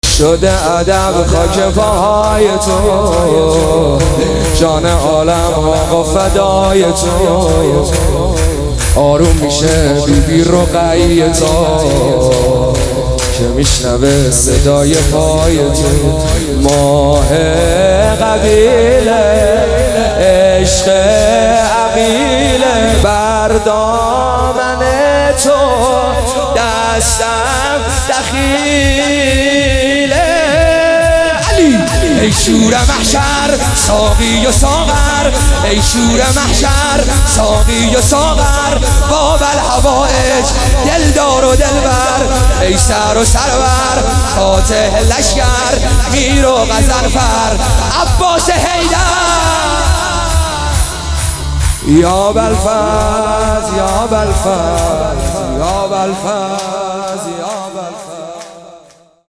میلاد حضرت عباس 99